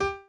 b_pianochord_v100l16o5g.ogg